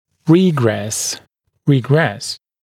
[‘riːgres] гл. [riː’gres][‘ри:грэс] гл. [ри:’грэс]регресс, движение назад; регрессировать